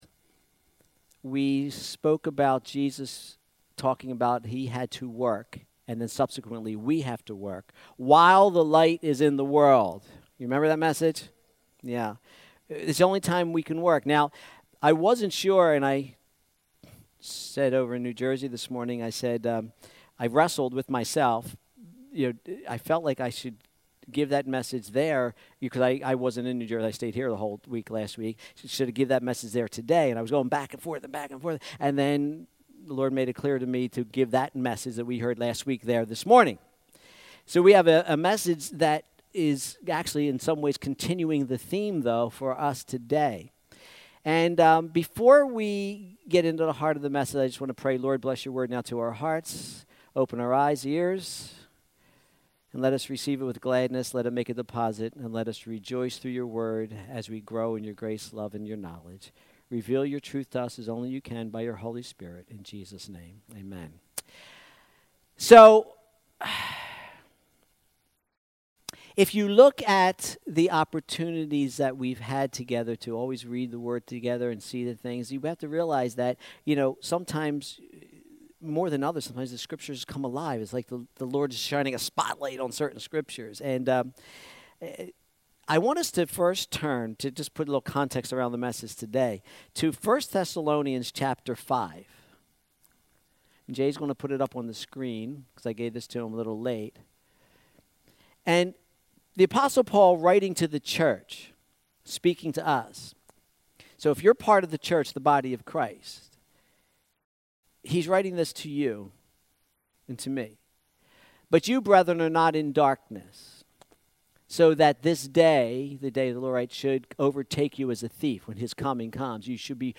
Sermons | First Christian Assembly